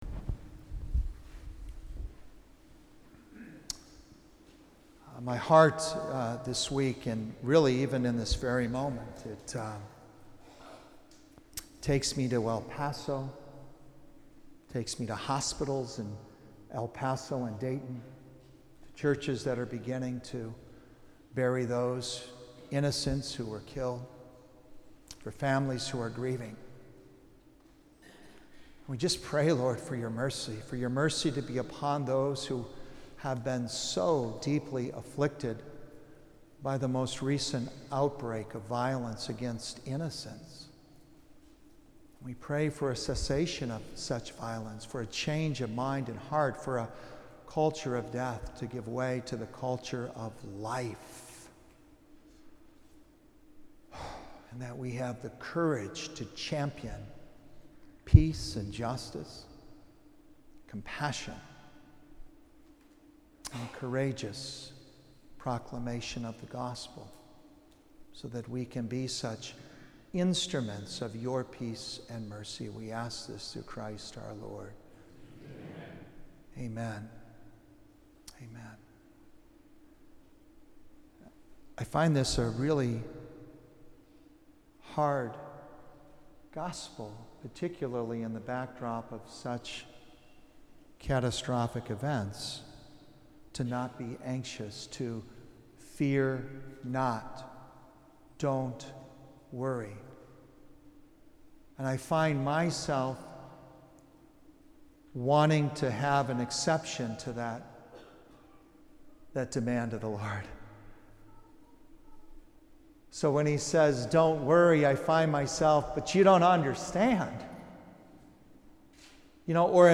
19th Sunday in Ordinary Time Homily